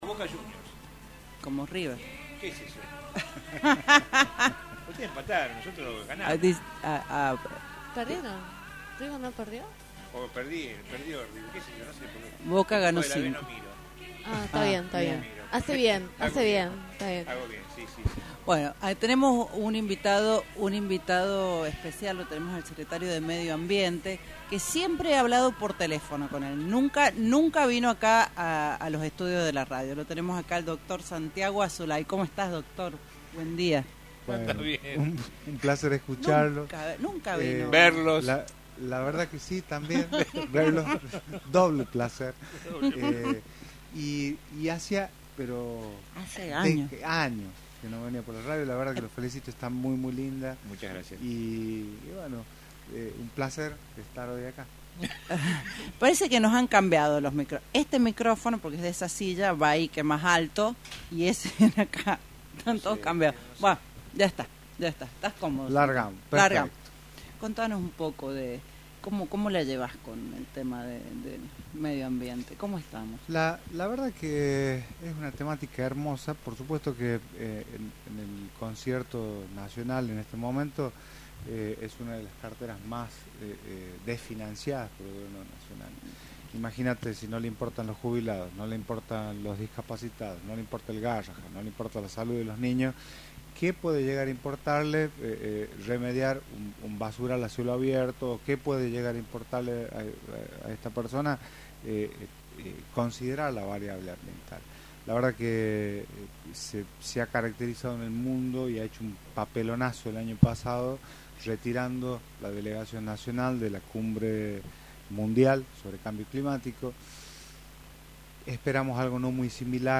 Gracias al Gobernador Ricardo Quintela, la cuestion ambiental se eleva a la legislatura con un artículo relacionado al cuidado ambiental, dijo en radio Libertad La Rioja, el secretario de medio ambiente Santiago Azulay
SANTIAGO AZULAY – SECRETARIO DE MEDIO AMBIENTE